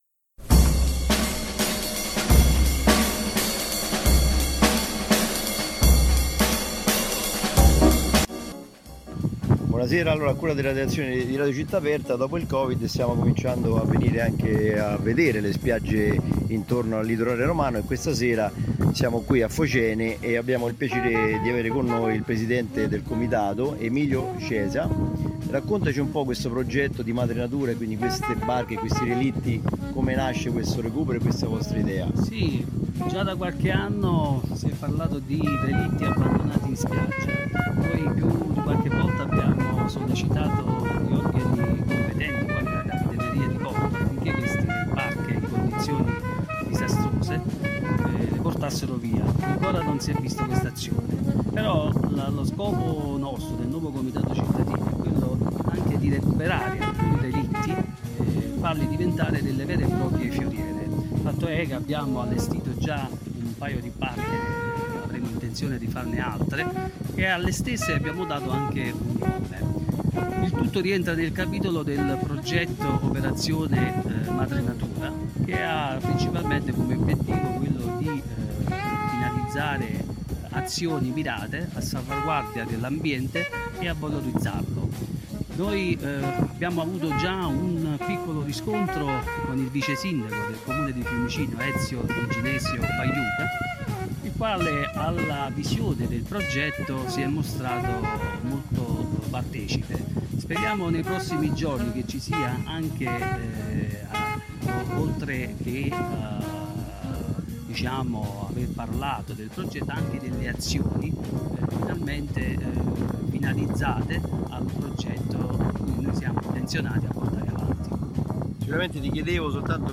Interviste Protagonisti progetto “Madre Natura” litorale FOCENE | Radio Città Aperta